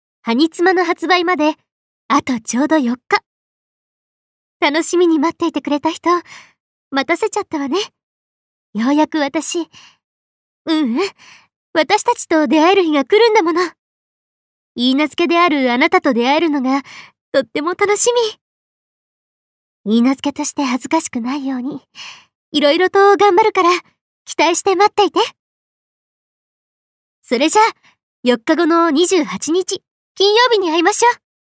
カウントダウンボイス開始！！発売まであと4日！